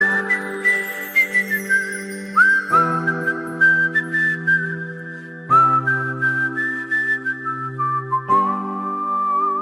Kategorien SMS Töne